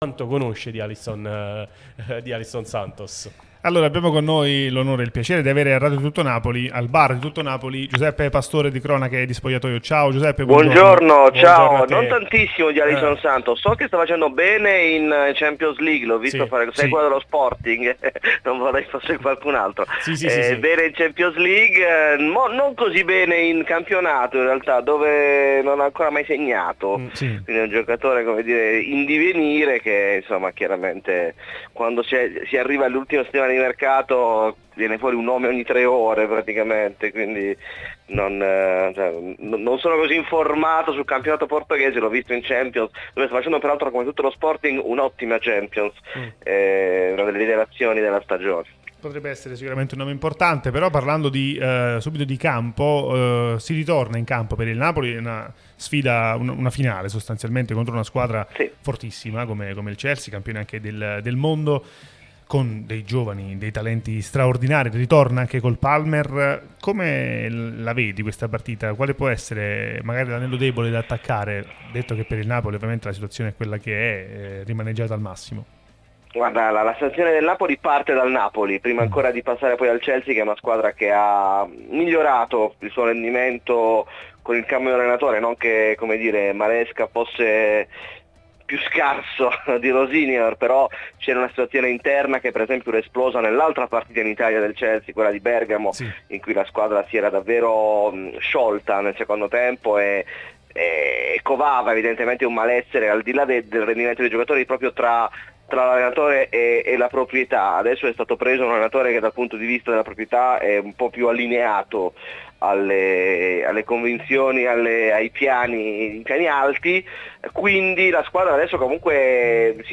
Radio Tutto Napoli